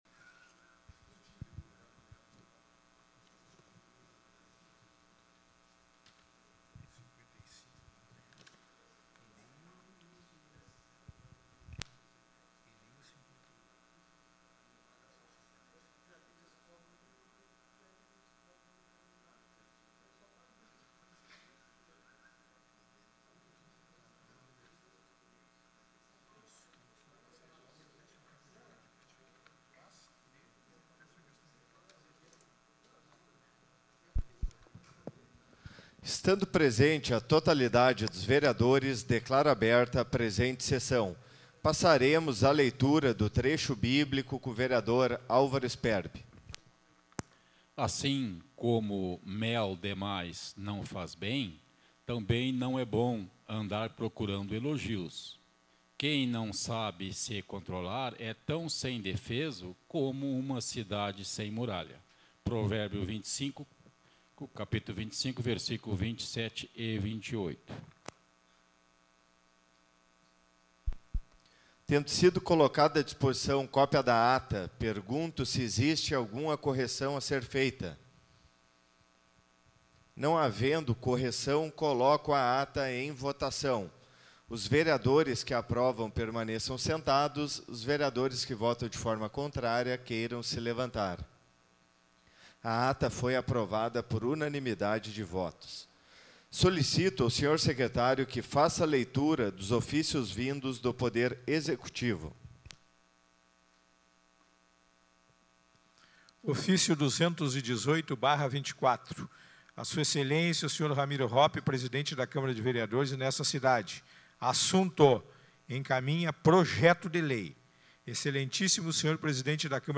Áudio Sessão 25.11.2024